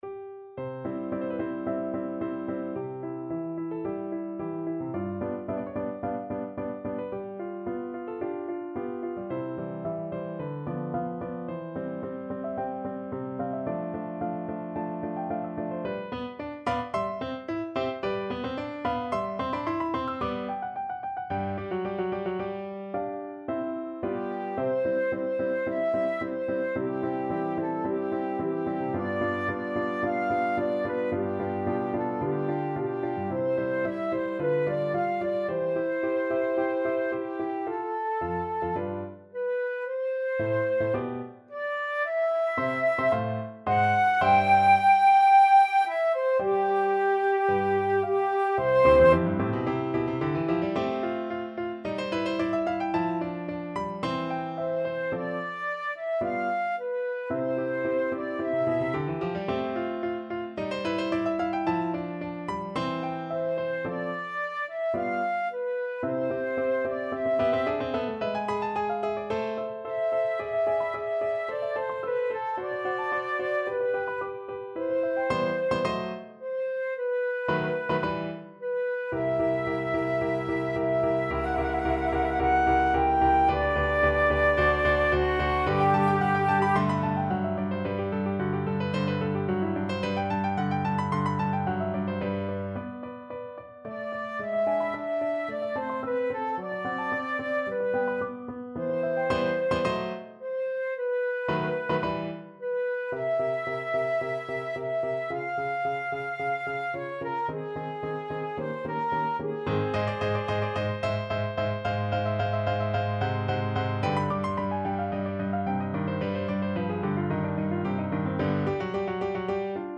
Classical Haydn, Franz Josef In Native Worth from 'The Creation' Flute version
Play (or use space bar on your keyboard) Pause Music Playalong - Piano Accompaniment Playalong Band Accompaniment not yet available reset tempo print settings full screen
Flute
C major (Sounding Pitch) (View more C major Music for Flute )
Andante =110
4/4 (View more 4/4 Music)
Classical (View more Classical Flute Music)